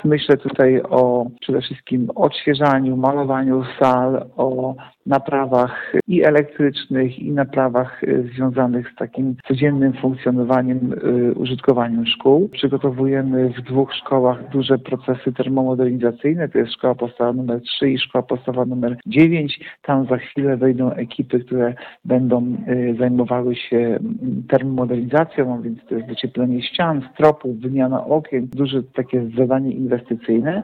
– Nieobecność dzieci wykorzystujemy do przeprowadzanie prac remontowych i utrzymaniowych, które planowane były na czas wakacji – mówi Artur Urbański, zastępca prezydenta Ełku.